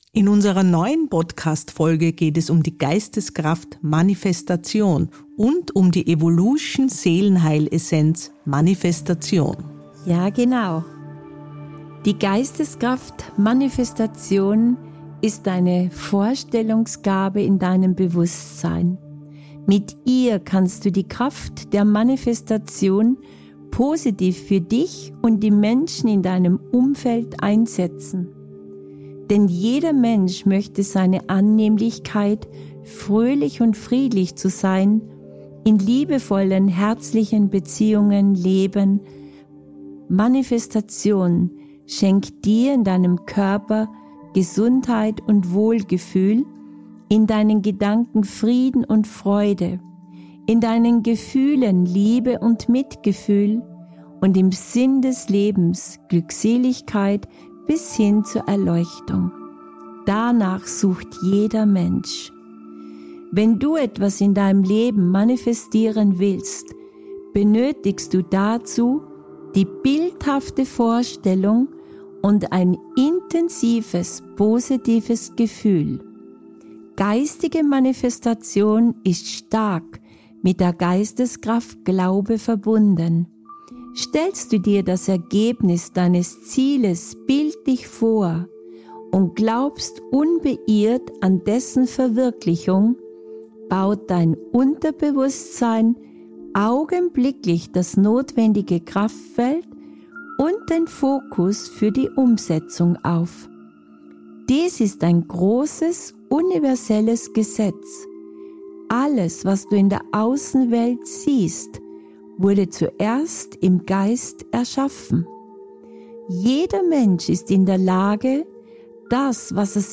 Die angeleitete Mentalübung für die Aktivierung deiner Manifestationskraft am Ende dieser Audioepisode soll Veränderung und Fülle in dein Leben bringen.